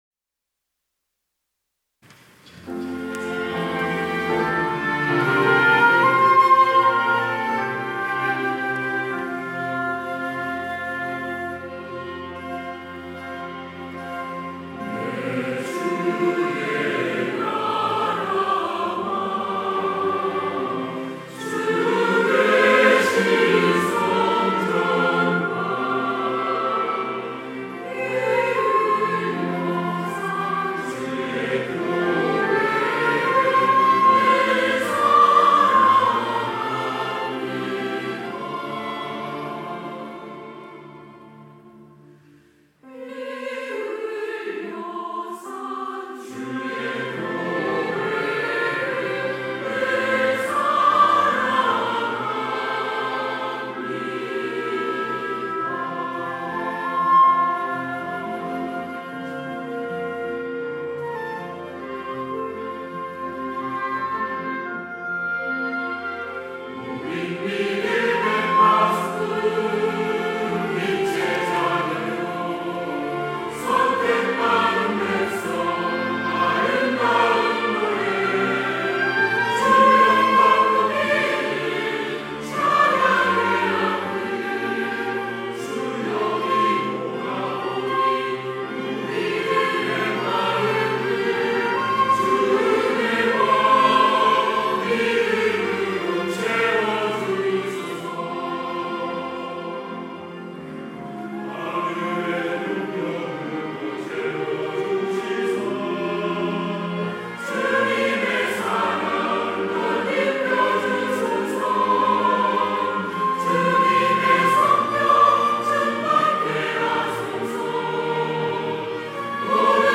시온(주일1부) - 믿음의 파수꾼 되게 하소서
찬양대